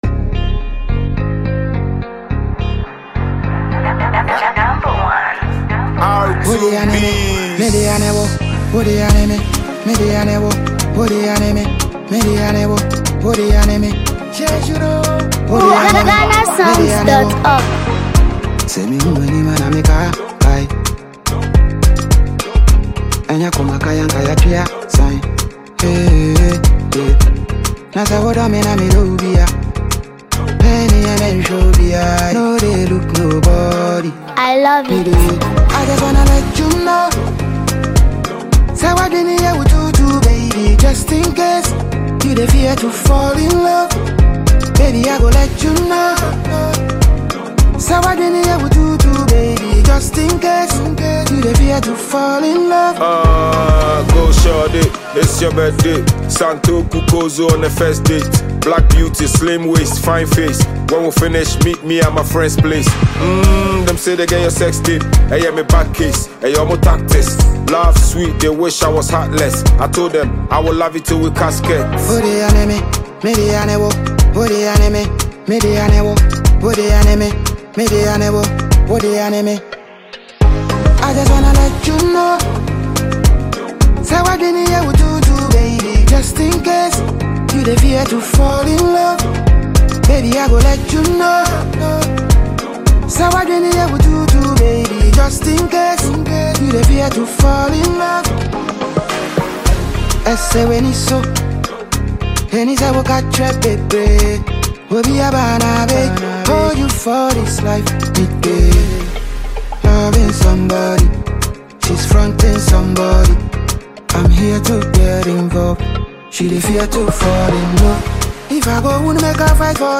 Ghana’s award-winning music duo